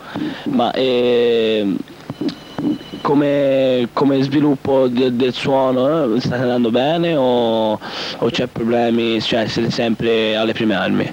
MusicaDroga Intervista.